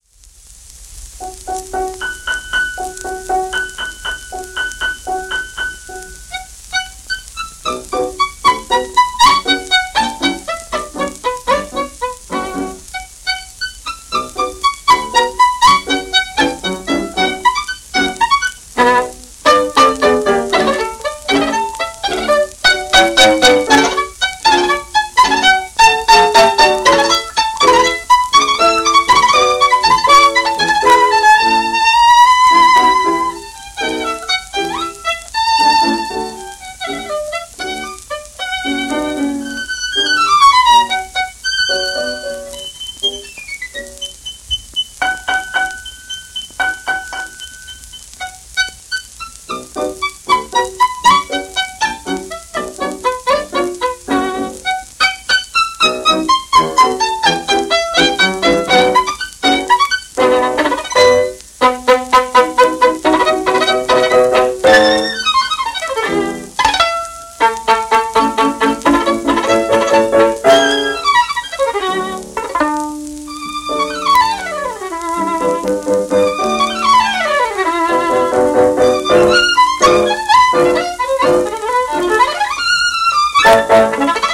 SP盤ですが電気再生用です。